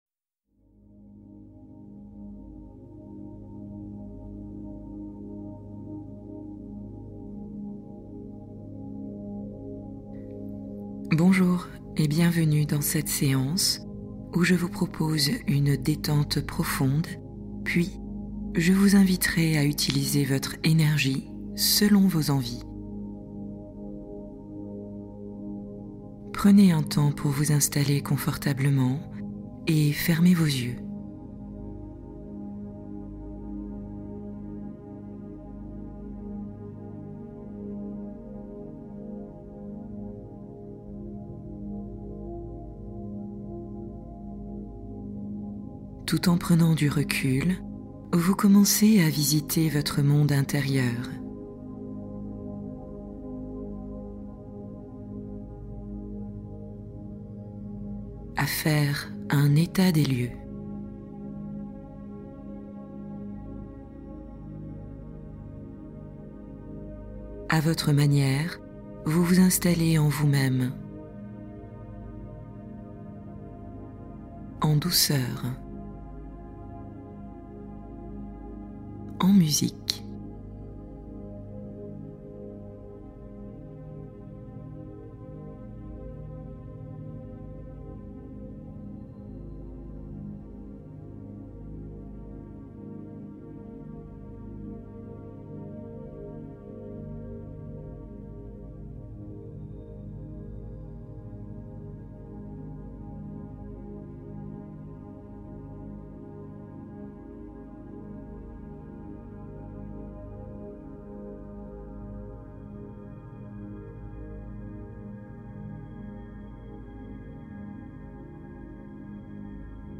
Clarifier l’esprit : guidance douce pour retrouver une direction intérieure